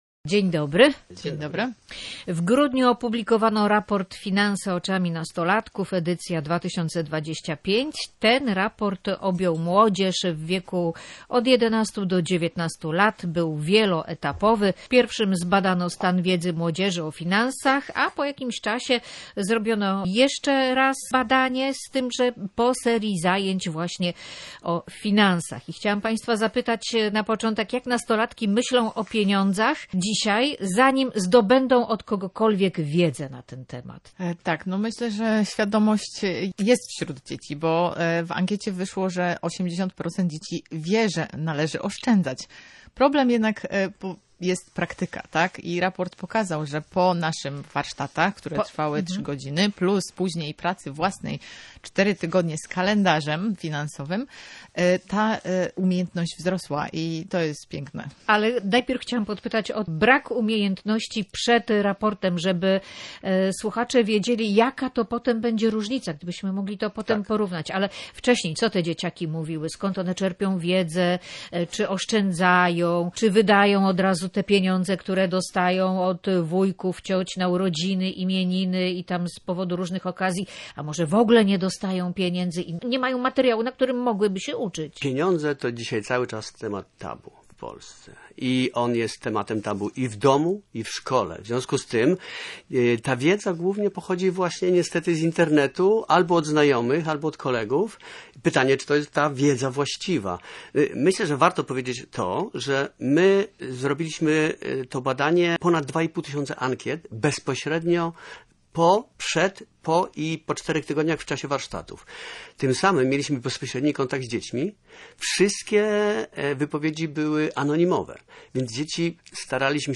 Wywiad_gospodarczy_finanse_i_dzieci.mp3